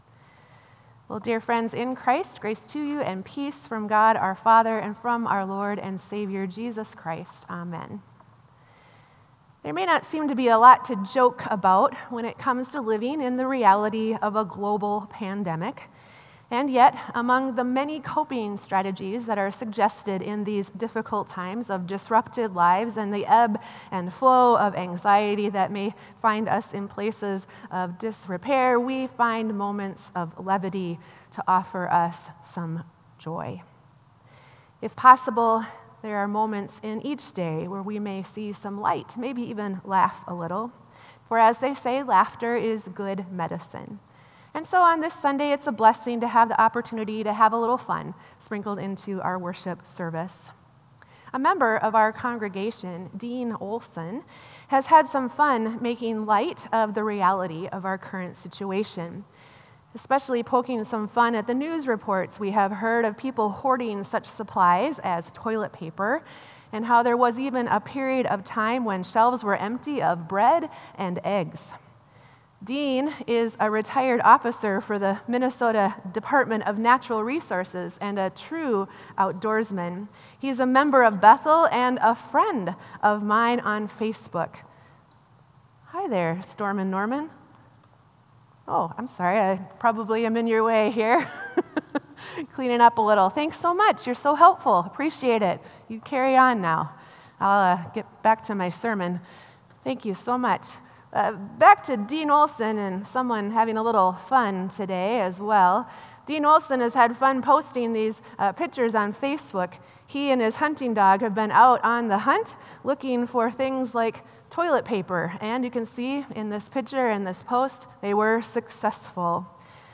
Sermon “Never Alone”